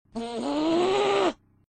angry.mp3